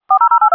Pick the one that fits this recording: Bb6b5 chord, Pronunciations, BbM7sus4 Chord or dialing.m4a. dialing.m4a